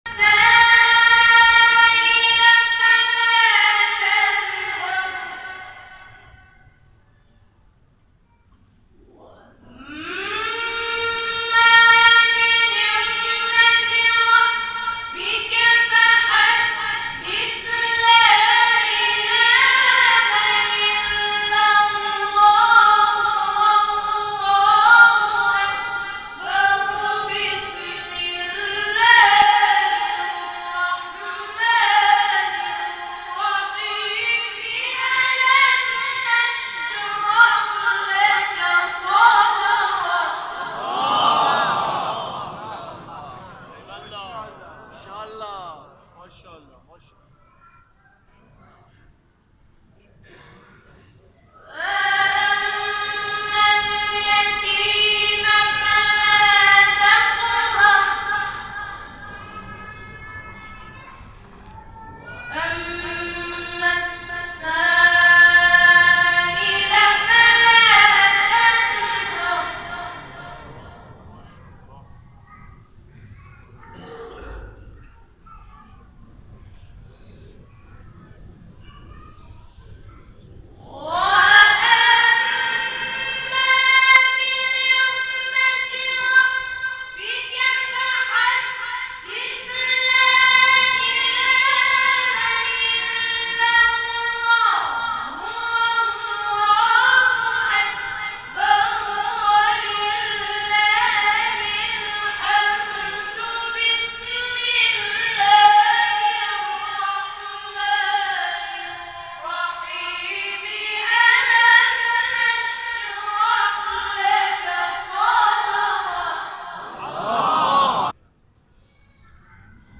گروه فعالیت‌های قرآنی: گروه همخوانی سبیل‌الرشاد در ماه مبارک رمضان در هیئات و محافل انس با قرآن به اجرای آثار خود پرداختند.
در پایان قطعه‌ای از اجرای این گروه در مسجد قاسم بن الحسن(ع) ارائه می‌شود.